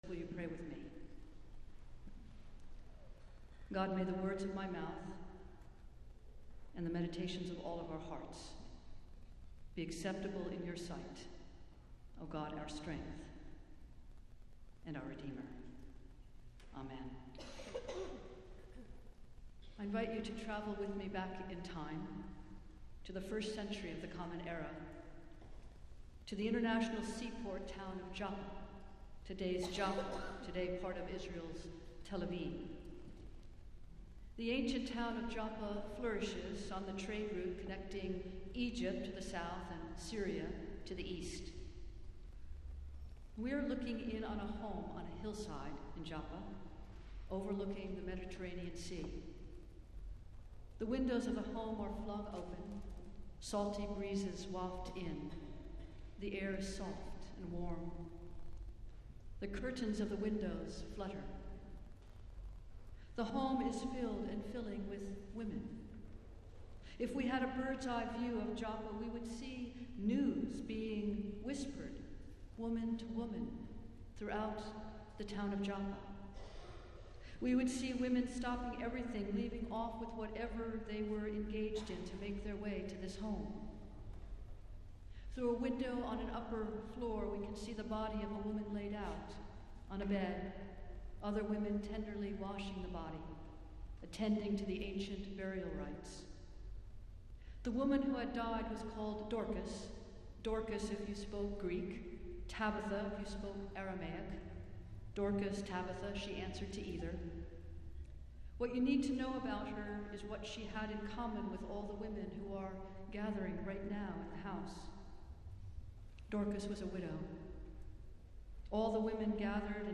Festival Worship - Twentieth Sunday after Pentecost